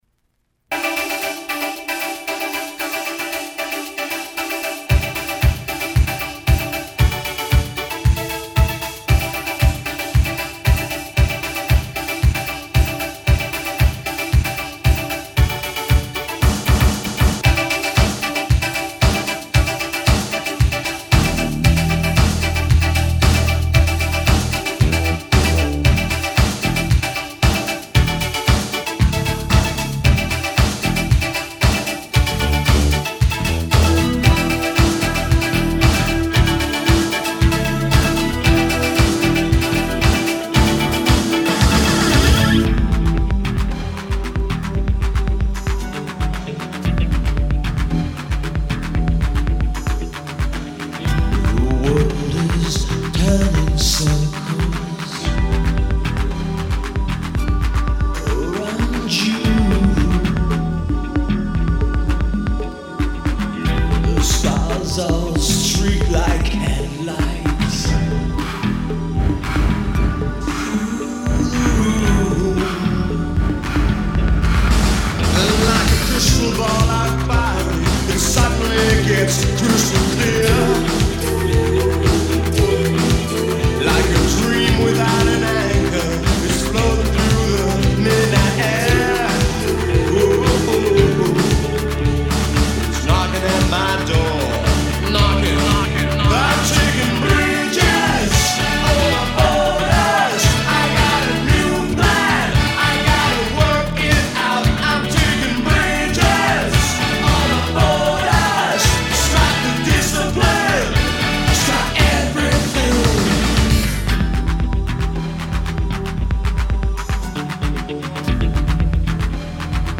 DJ Only 12inch A-side Dance Mix